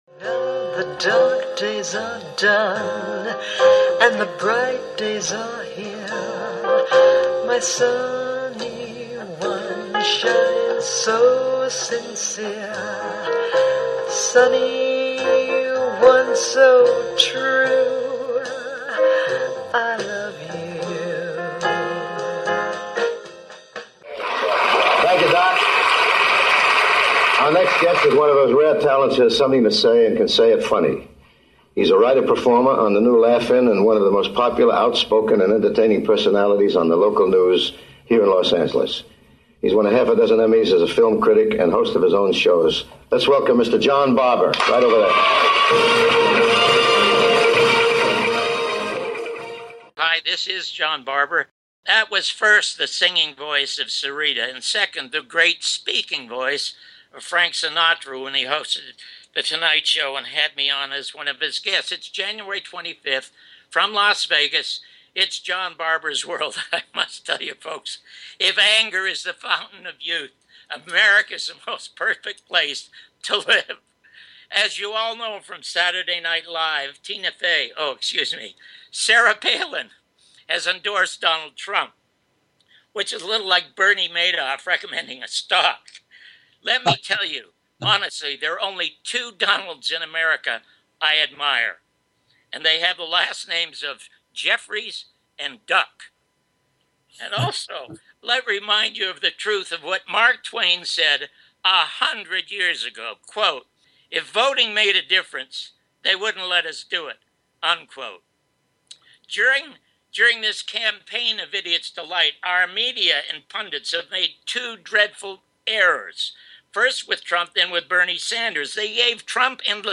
Guest, Mike Gravel
1st Part interview with Senator Mike Gravel